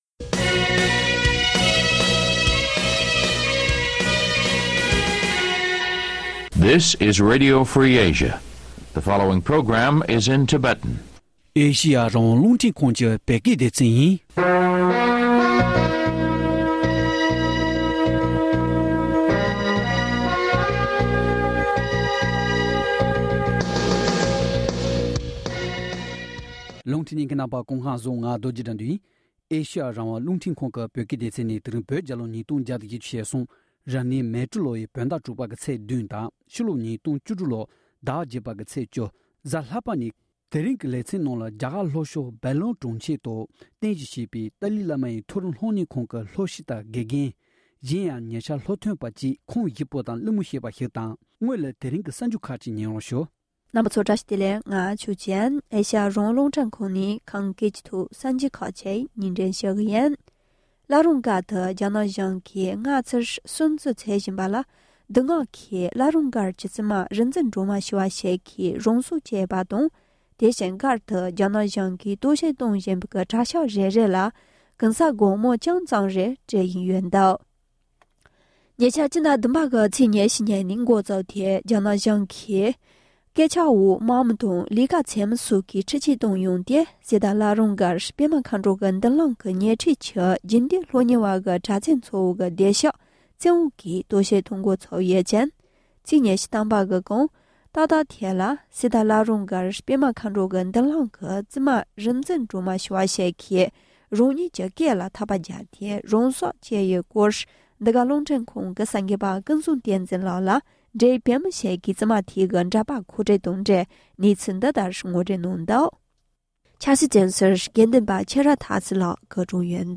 ༄༅༎ཐེངས་འདིའི་ཁ་བའི་གྲོས་ར་ལེ་ཚན་ནང་རྒྱ་གར་ལྷོ་ཕྱོགས་སྦེང་ལོར་གྲོང་འཁྱེར་དུ་ (Bangalore) རྟེན་གཞི་བྱས་པའི་ཏཱ་ལའི་བླ་མའི་མཐོ་རིམ་སློབ་གཉེར་ཁང་ཐོག་མ་གསར་འཛུགས་ཀྱི་དགོས་དོན་སྙིང་པོ་དང་ད་ལྟའི་བར་ལས་དོན་ཆེ་བ་ཁག་གང་རེ་སྒྲུབ་ཐུབ་ཡོད་མེད་དང་ཡང་མ་འོངས་པའི་ཁ་ཕྱོགས་སྐོར་བགྲོ་གླེང་བྱེད་རྒྱུ་ཡིན་།